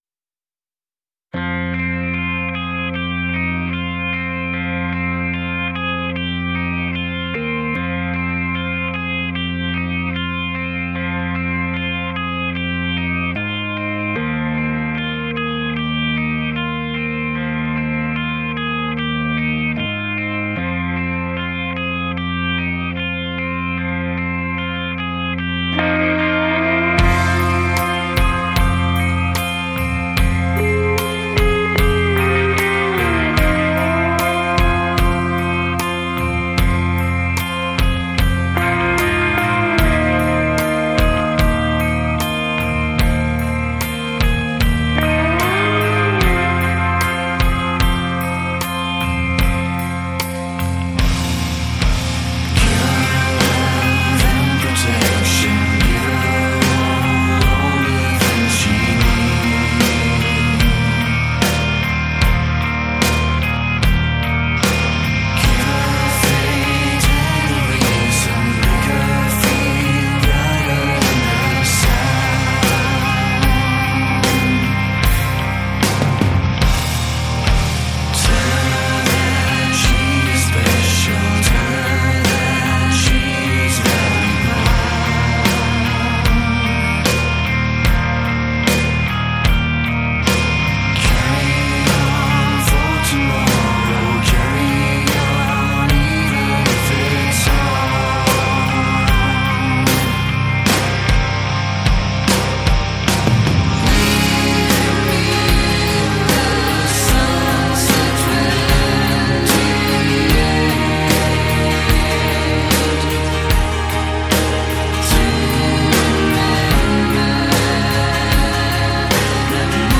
Жанр: gothicmetal